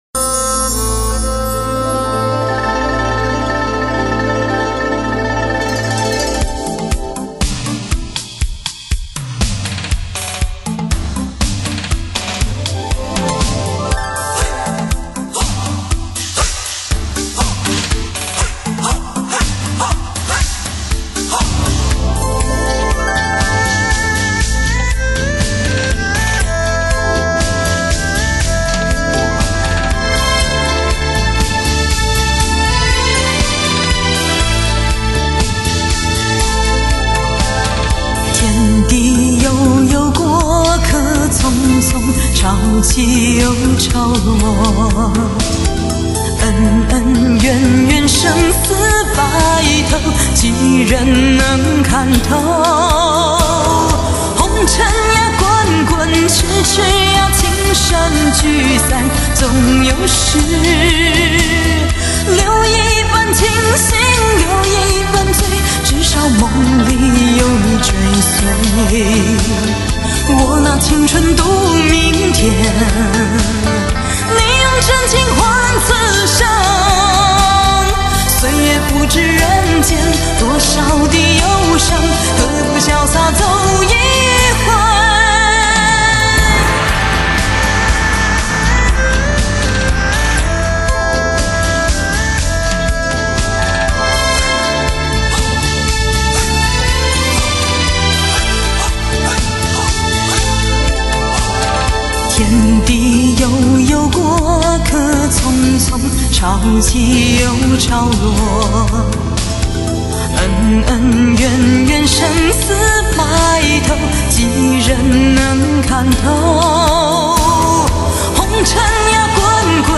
發燒極品，百聽不厭;全頻段六聲道製作如同置身音樂聽之中。傳奇真空管處理，頂級音效環繞體验！